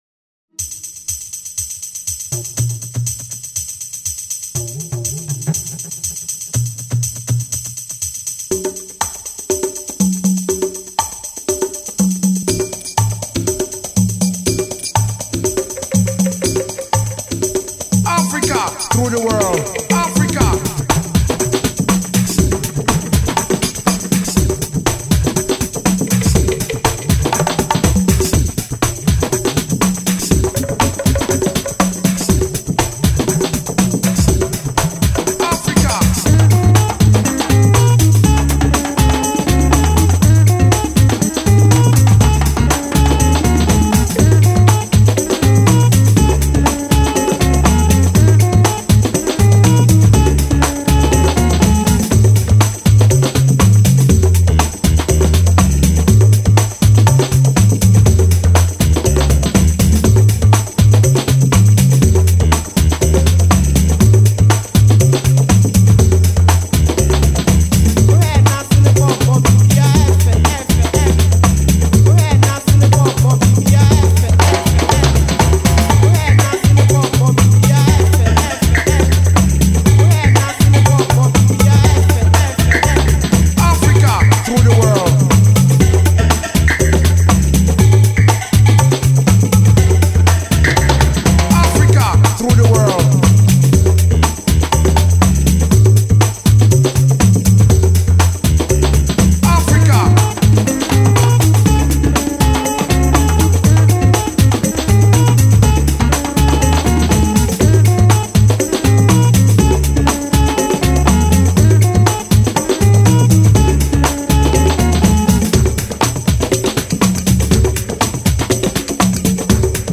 Allegro [120-130] joie - percussions - fete - danse - voyage